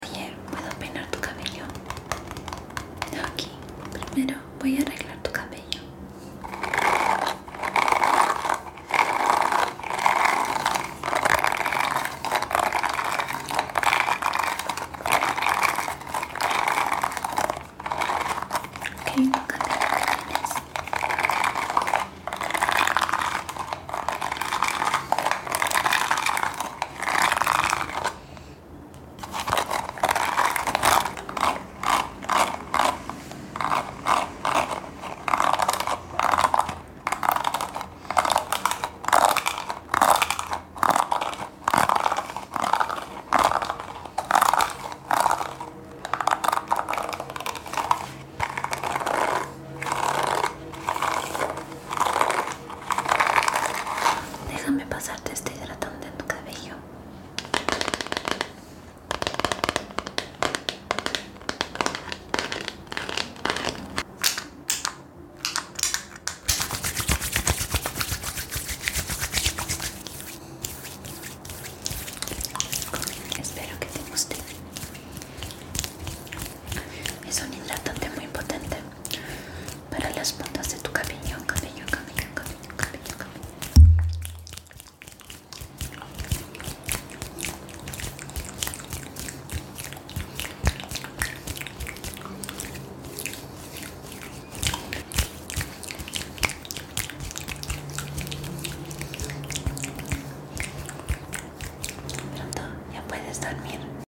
asmr peinando tu cabello 🥰❤ sound effects free download